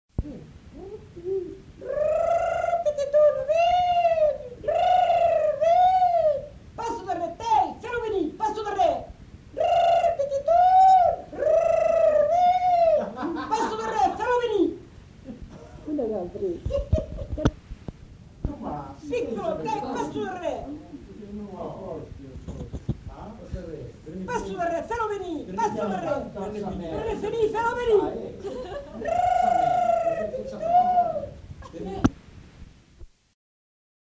Appel au bétail
Aire culturelle : Couserans
Lieu : Prat-Communal (lieu-dit)
Genre : expression vocale
Type de voix : voix de femme
Production du son : crié
Classification : appel au bétail